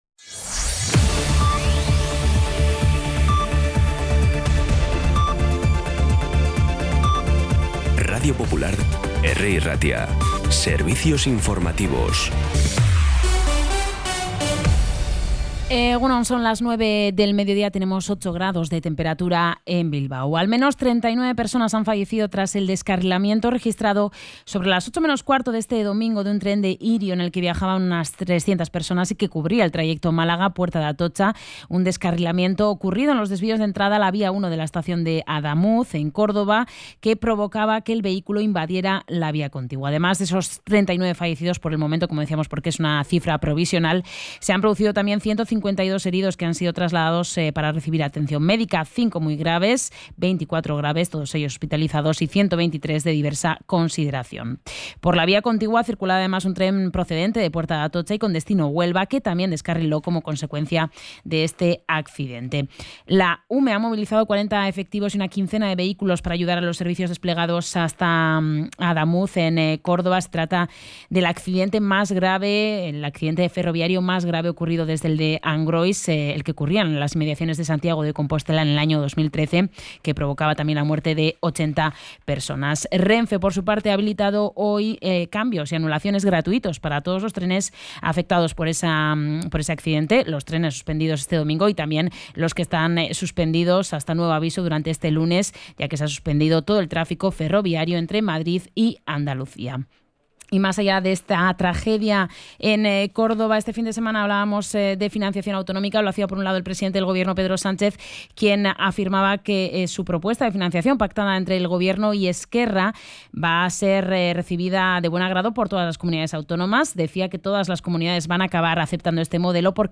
Podcast Informativos
Los titulares actualizados con las voces del día.